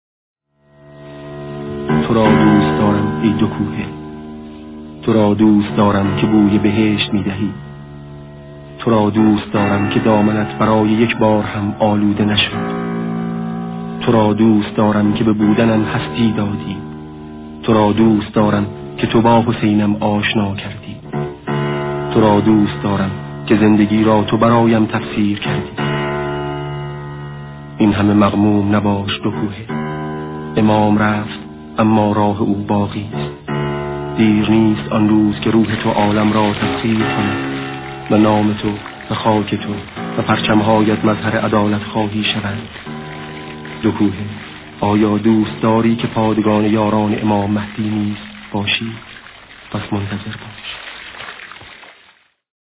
قسمتی از صدای ماندگار شهید سید مرتضی آوینی دربارۀ دوکوهه، معبری به آسمان